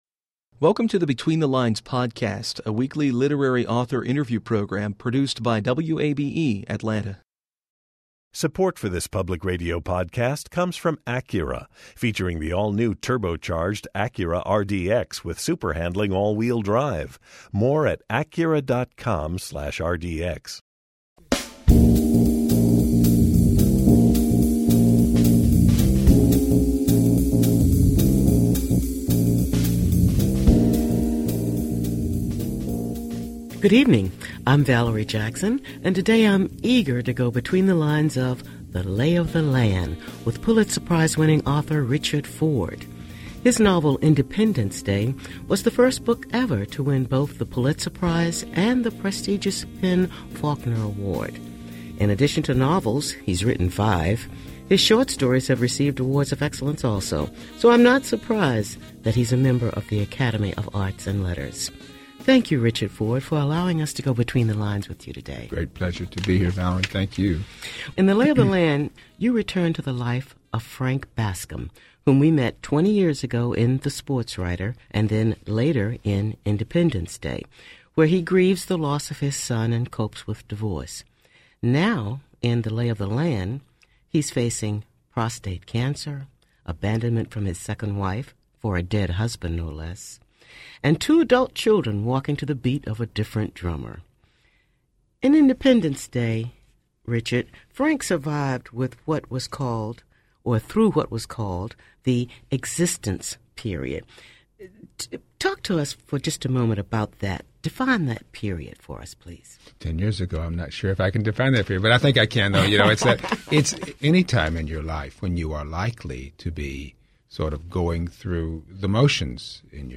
Interview with Richard Ford discussing his new book: Lay of the Land